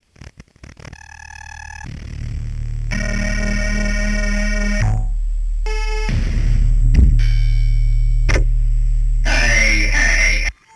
6_emax-malfunction.wav